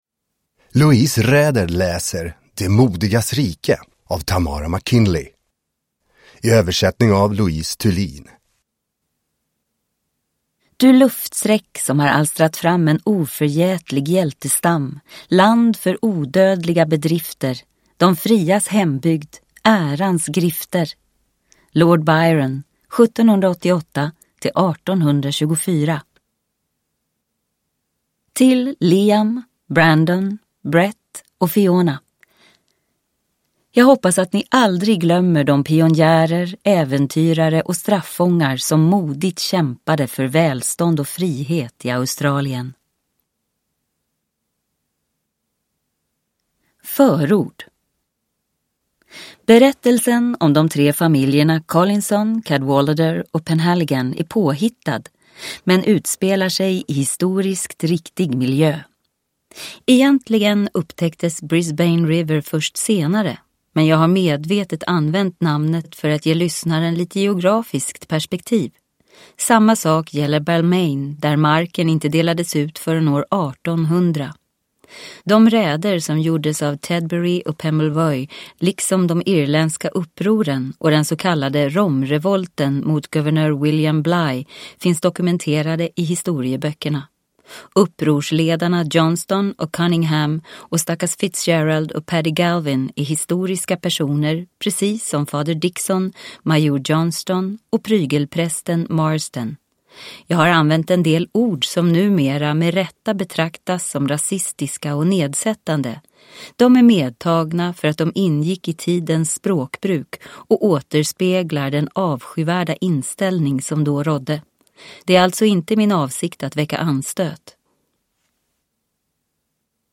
De modigas rike – Ljudbok